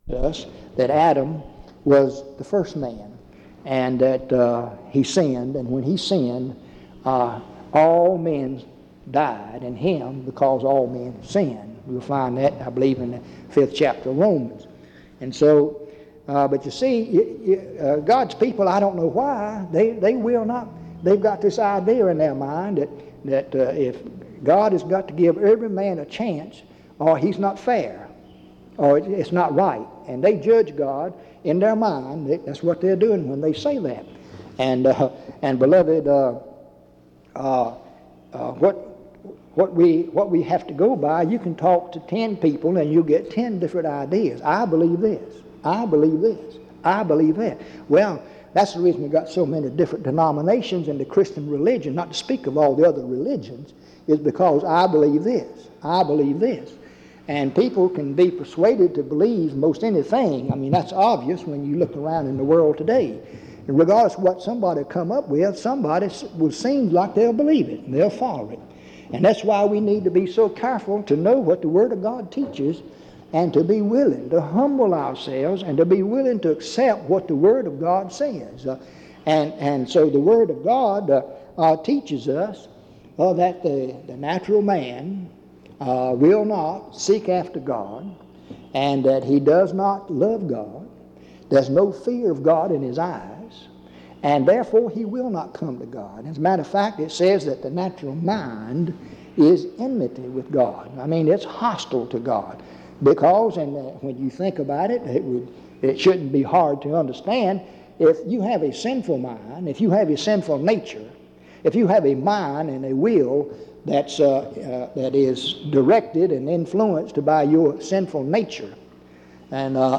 In Collection: Reidsville/Lindsey Street Primitive Baptist Church audio recordings Miniaturansicht Titel Hochladedatum Sichtbarkeit Aktionen PBHLA-ACC.001_057-A-01.wav 2026-02-12 Herunterladen PBHLA-ACC.001_057-B-01.wav 2026-02-12 Herunterladen